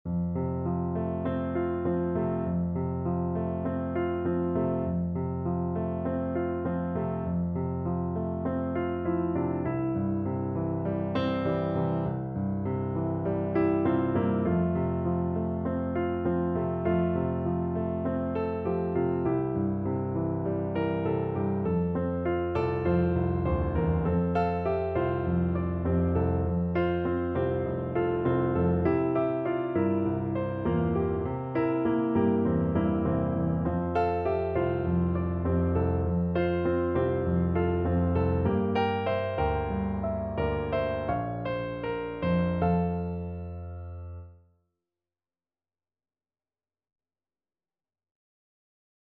Play (or use space bar on your keyboard) Pause Music Playalong - Piano Accompaniment Playalong Band Accompaniment not yet available reset tempo print settings full screen
F major (Sounding Pitch) C major (French Horn in F) (View more F major Music for French Horn )
Gently Flowing = c.100
4/4 (View more 4/4 Music)